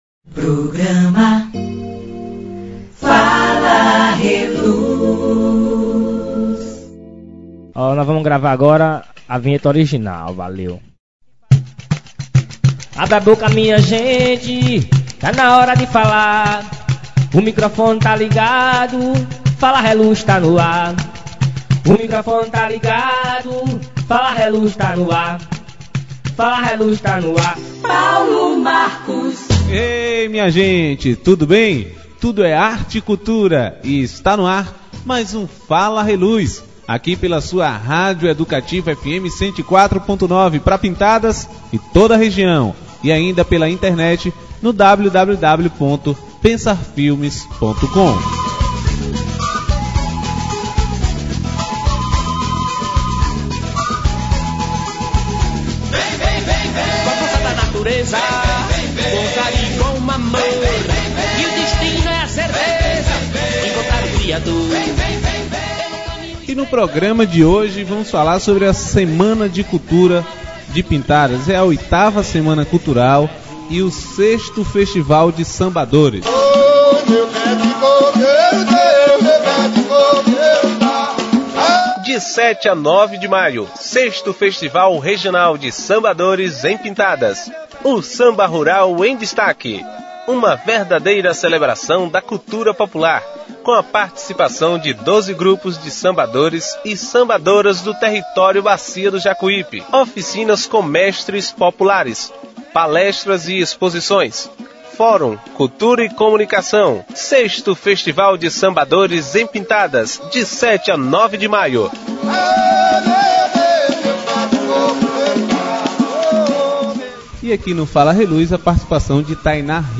E mais: uma reportagem especial sobre o 1º Festival de Música Canto da Terra.